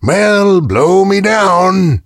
gale_die_vo_04.ogg